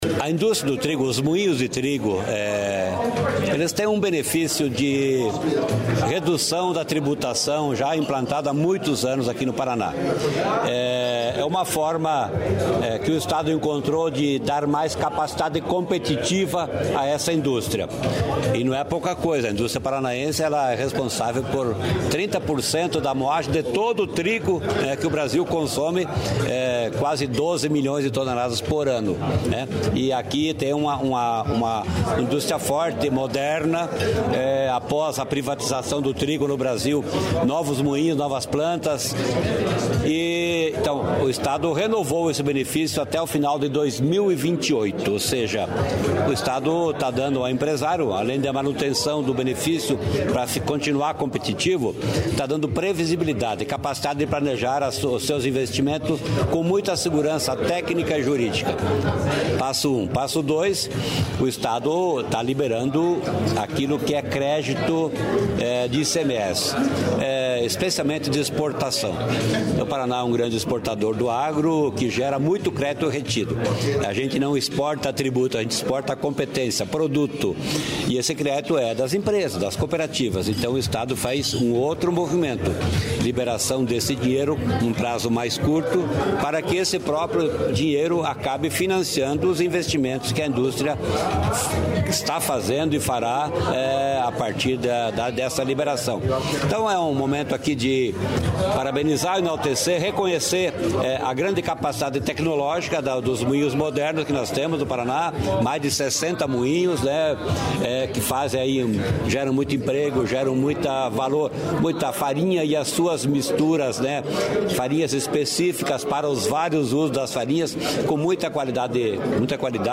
Sonora do secretário da Fazenda, Norberto Ortigara, sobre a competitividade da indústria de trigo do Paraná